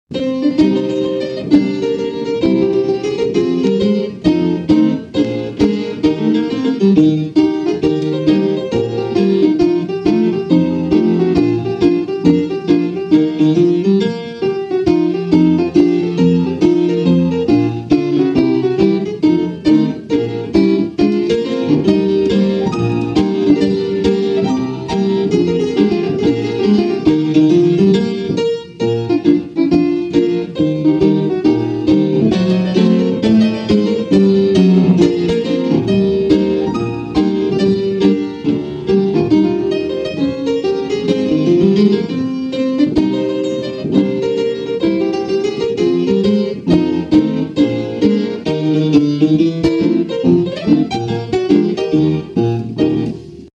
"Cartoonist and 'Walt and Skeezix' co-editor Chris Ware fakes his way through a wretchedly "low-fi" home recording of the 1923 Skeezix song in a coarse approximation of what the average American pianist might've been able to make of this completely forgotten composition 75 years ago. He does not honor the repeats, nor does anyone sing the lyrics; however, a complete pdf of the sheet music may be downloaded here."